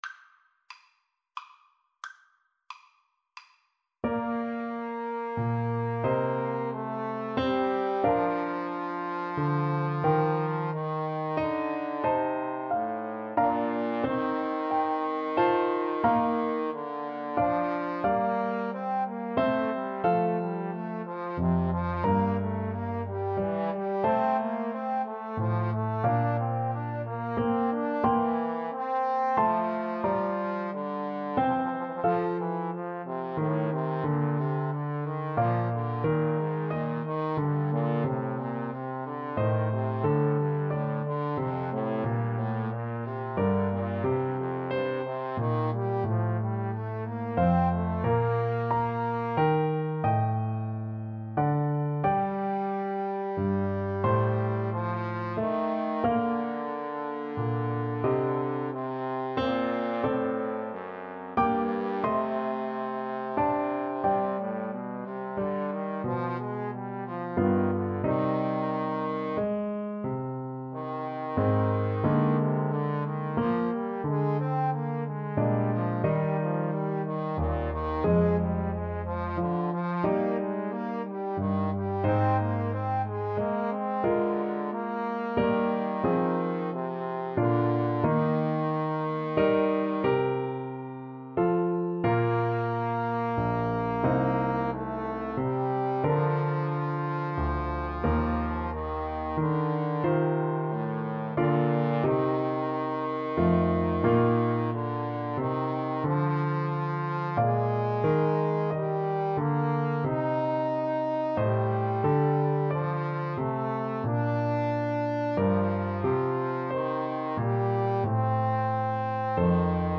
Largo ma non tanto ( = c. 90)
3/4 (View more 3/4 Music)